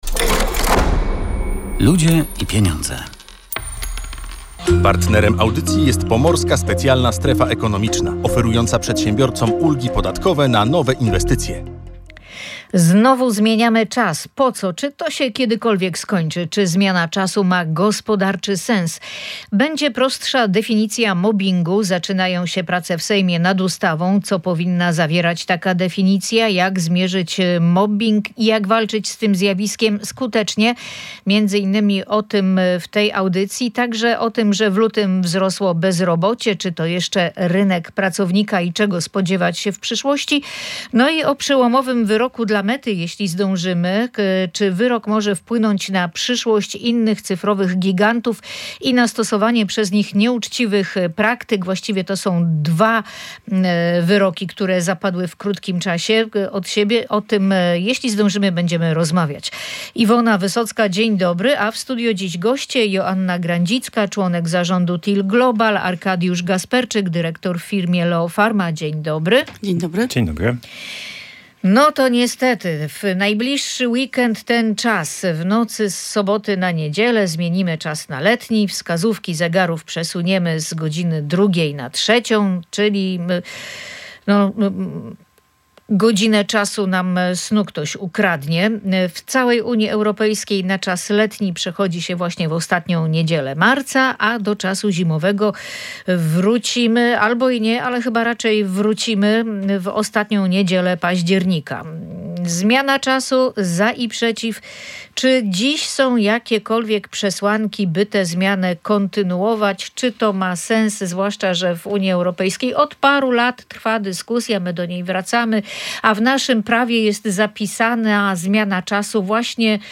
Między innymi na ten temat rozmawialiśmy w audycji „Ludzie i Pieniądze”.